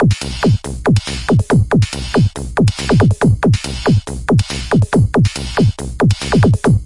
狂欢节拍
标签： 舞蹈 TECHNO 性交 俱乐部 狂欢
声道立体声